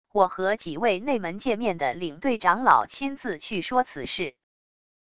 我和几位内门界面的领队长老亲自去说此事wav音频生成系统WAV Audio Player